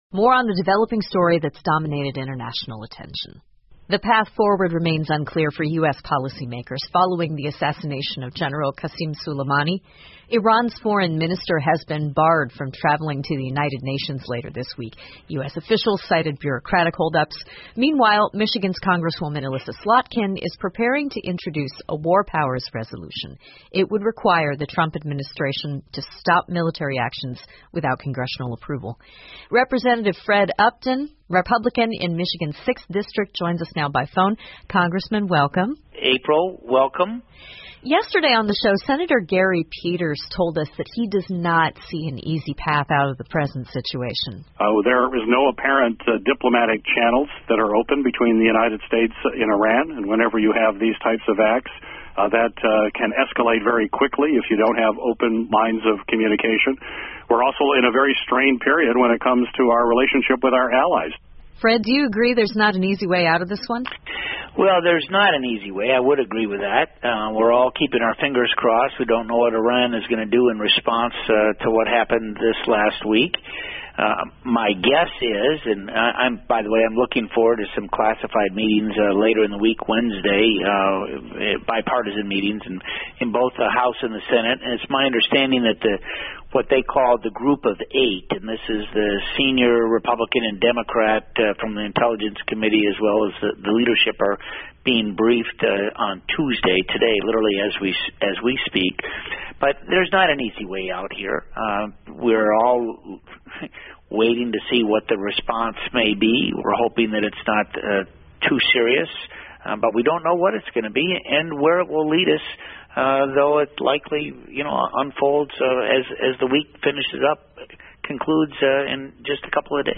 密歇根新闻广播 国会应对伊朗问题进行讨论 听力文件下载—在线英语听力室